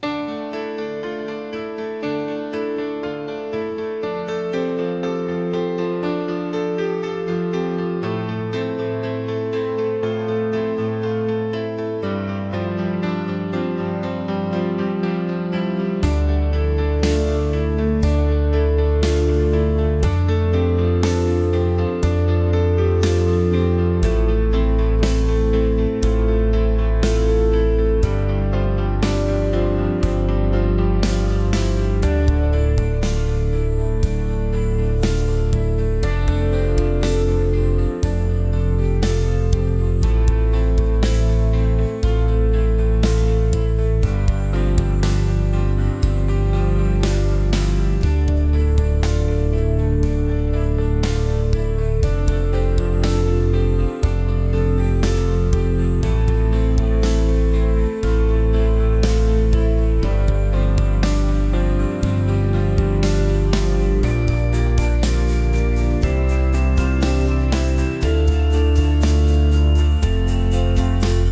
悲しい